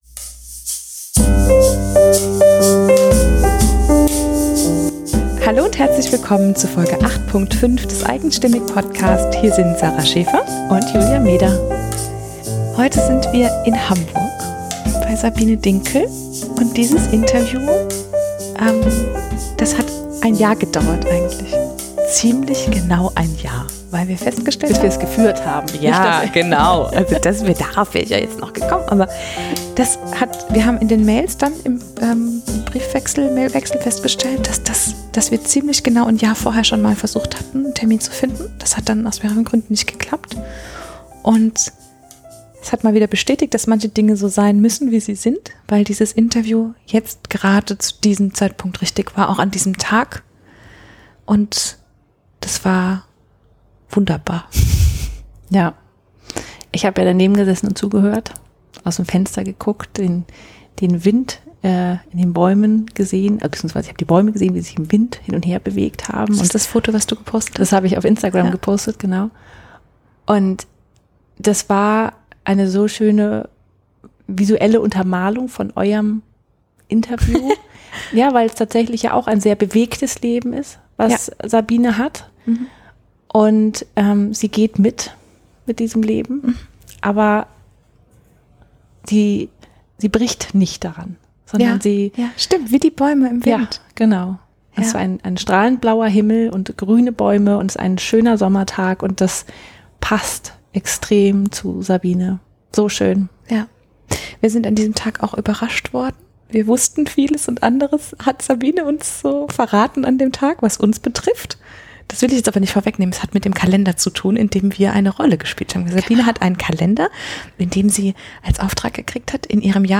Wie sie das macht, welche Themen das sind und warum sie in einem wilden Wirbel lebt, das erzählt sie am besten selbst. 54 Minuten 43.75 MB Podcast Podcaster eigenstimmig Interviews mit einzigartigen Frauen*, die lieben, was sie tun.